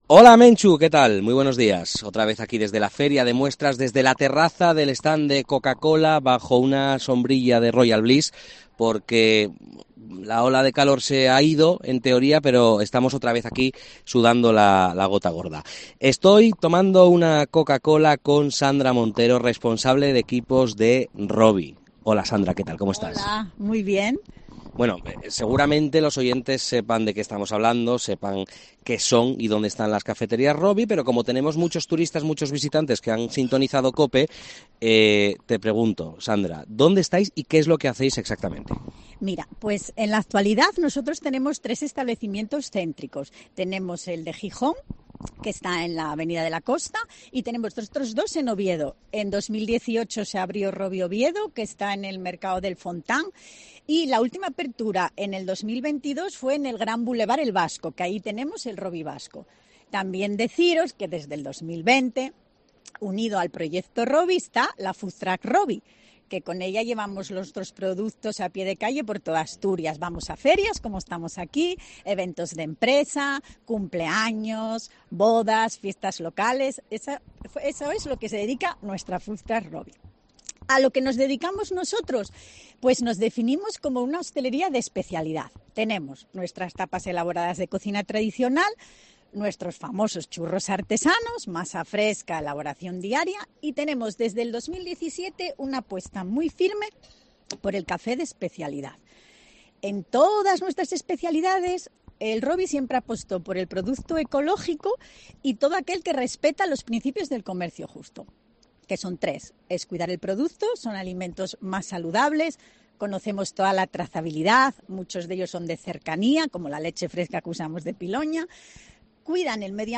En el especial de COPE desde la terraza del stand de Coca-Cola del Recinto Ferial Luis Adaro
entrevista